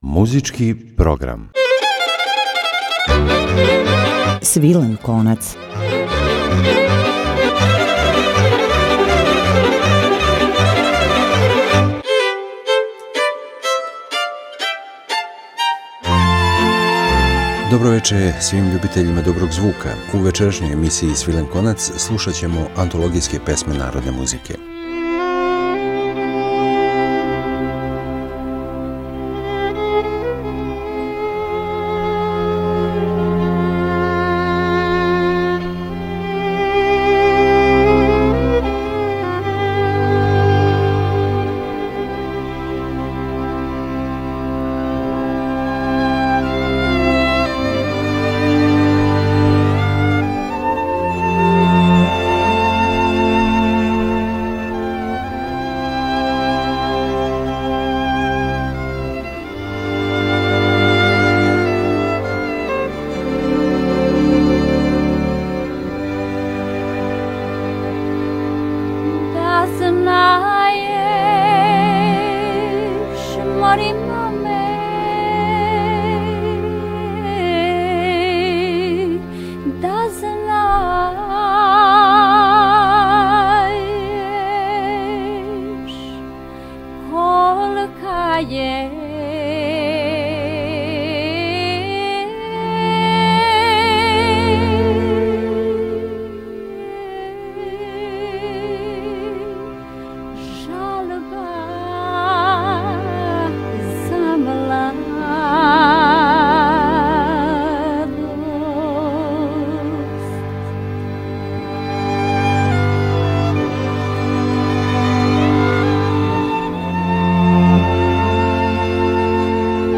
Музичка емисија “Свилен конац”